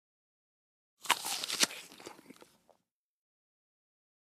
SingleAppleBite PE677805
DINING - KITCHENS & EATING APPLE: INT: Single bite into an apple.